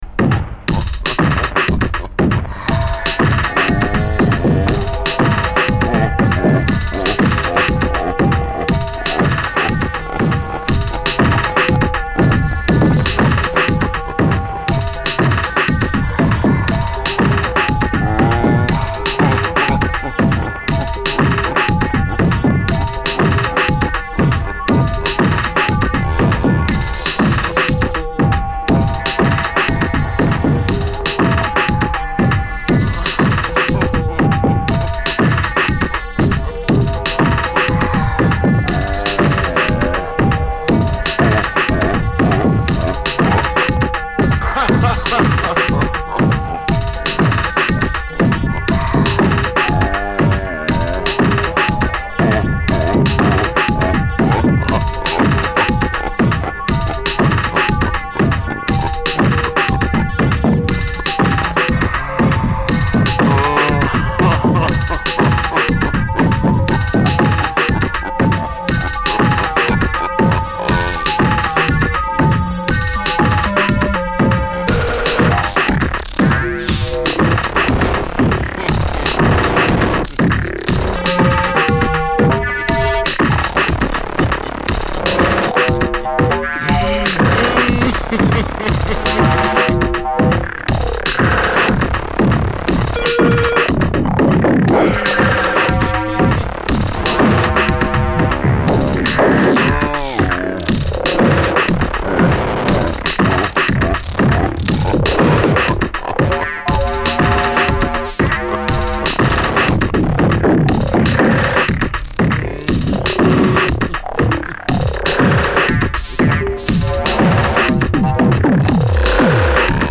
House Techno Acid